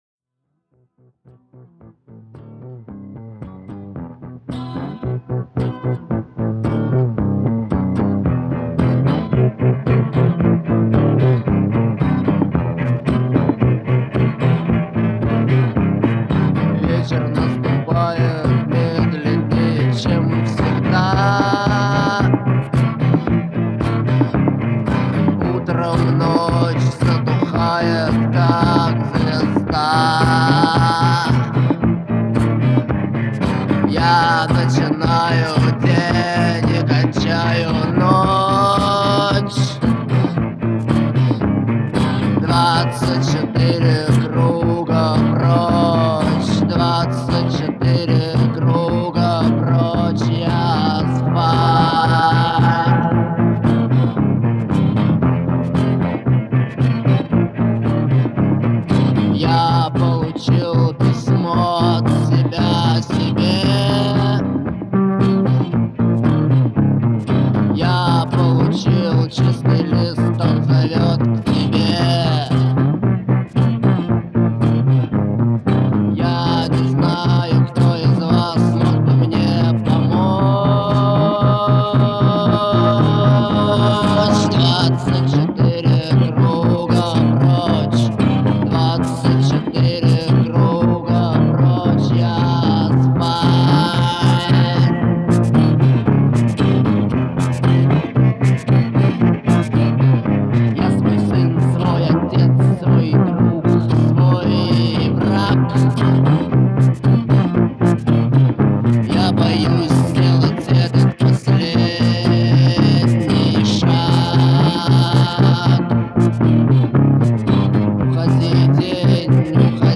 а также поклонникам русского рока.